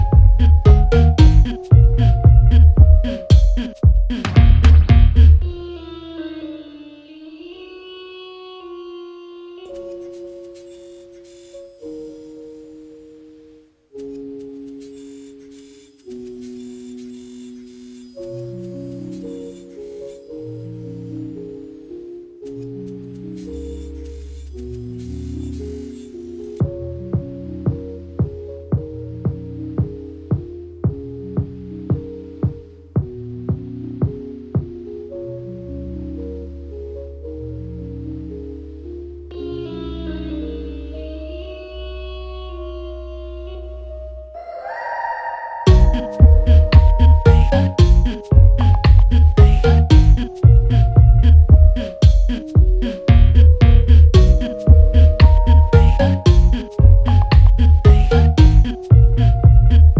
遊び心や実験性を感じさせる